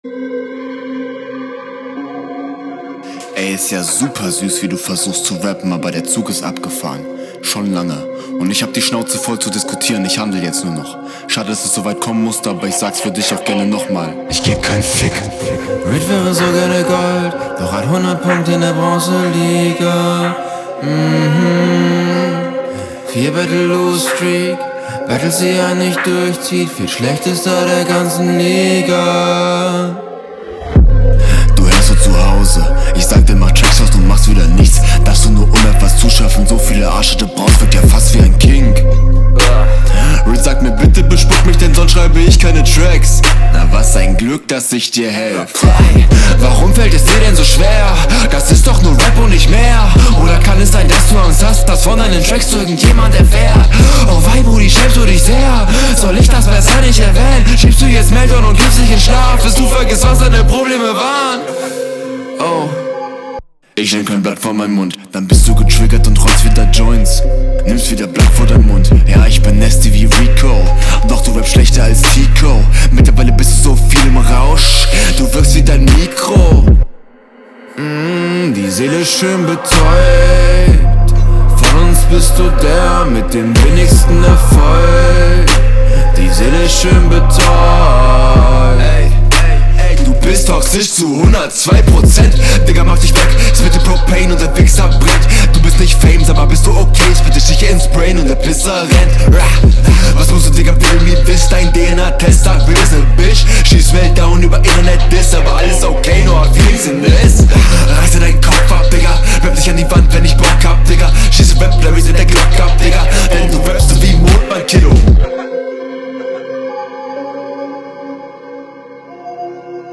HR2: Stimme wieder etwas laut; Flow ist ganz gut aber an manchen Stellen auch ein …